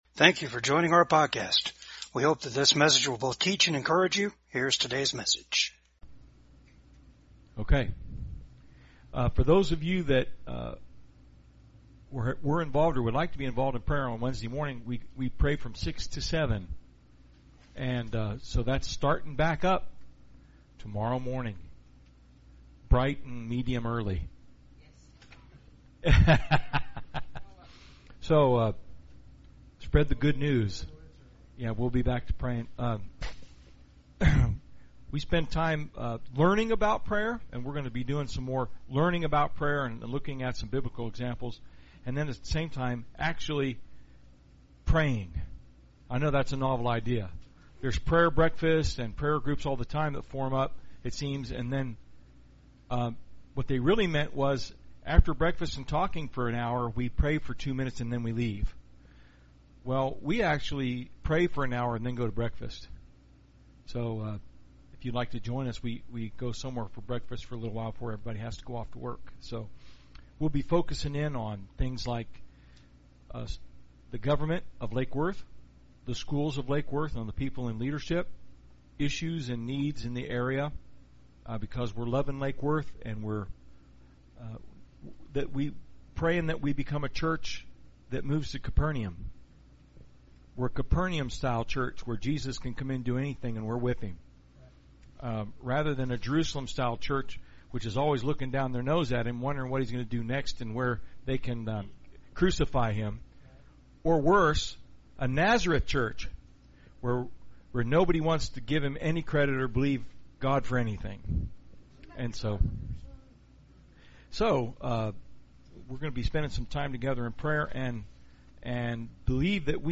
PORTIONS OF THIS AUDIO HAVE BEEN EDITED DUE TO COPYRIGHT RESTRICTIONS.